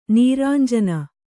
♪ nīrānjana